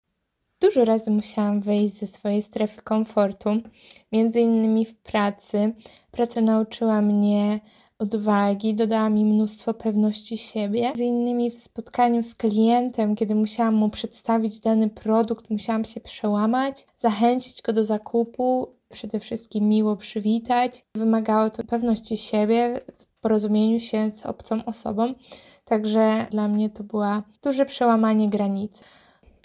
Zapytaliśmy studentki, czy były kiedyś w sytuacji, w której musiały opuścić swoją strefę komfortu i jak poradziły sobie z dostosowaniem do nowej sytuacji: